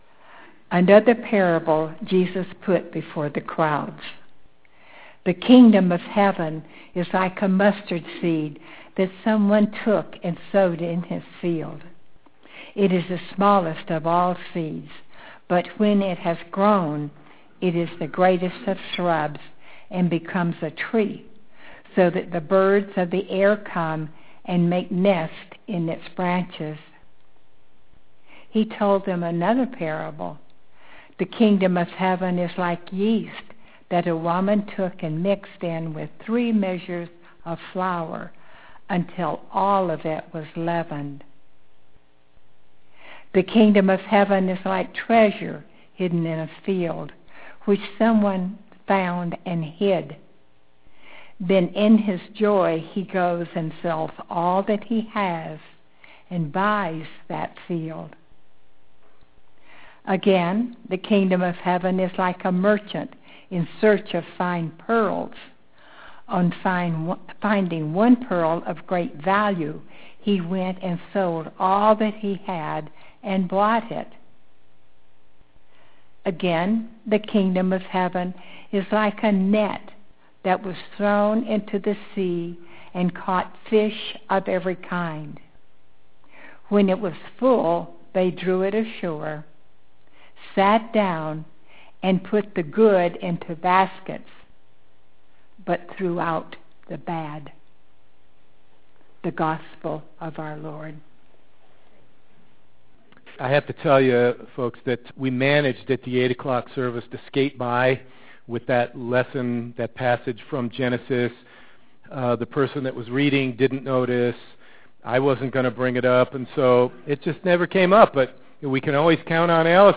A story from the book The Hidden Life of Trees (not “Secret Life”) is referenced in the sermon today.